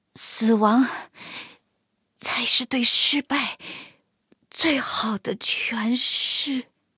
黑豹被击毁语音.OGG